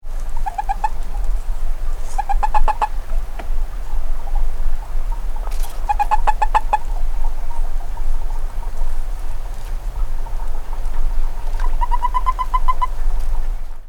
Sounds of California Toad - Anaxyrus boreas halophilus
The following recordings were made at night at a small artificial desert pond in San Diego County (shown below in daylight.)
Several male toads were calling occasionally while floating on the water hidden in reeds or only partially.
Sound This is 14 seconds of a short series of calls produced by two male toads.